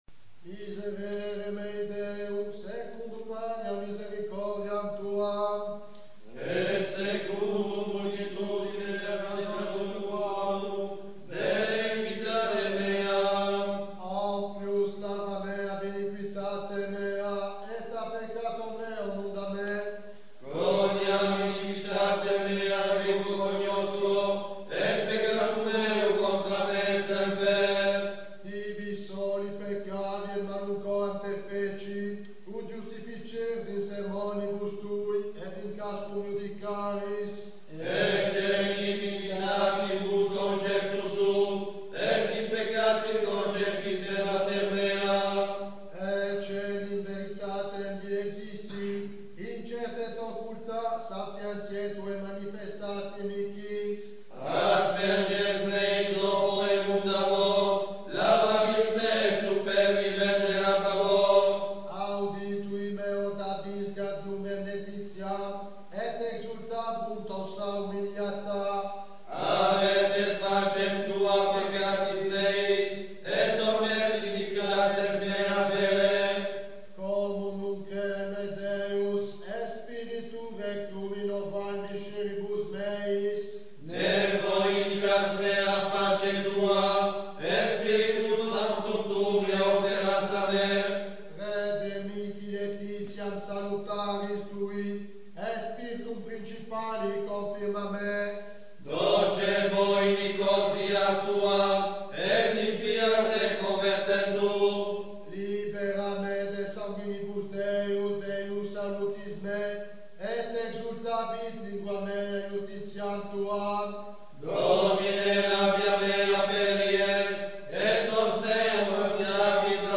Cantiques jeudi et vendredi Saint · FR